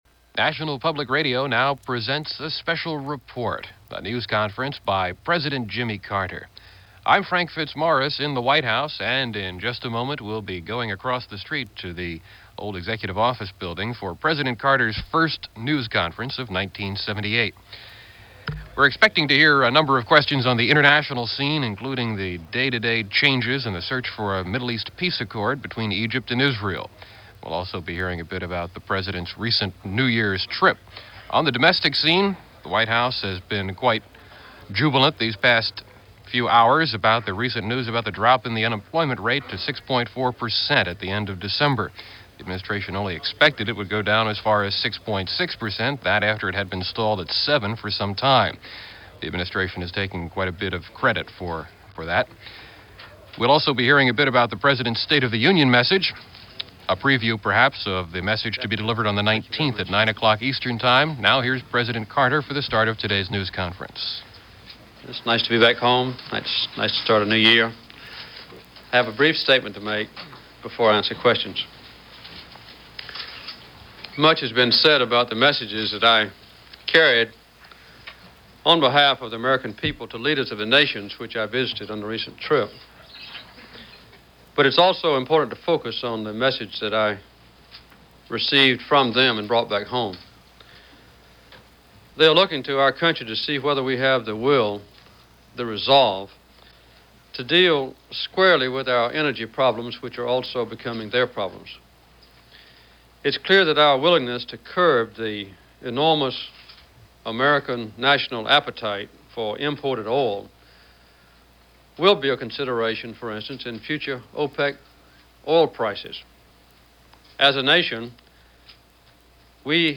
Jimmy Carter Press Conference -Starting The Political Year - January 12, 1978
This day in 1978 saw President Jimmy Carter give his first Press Conference of 1978.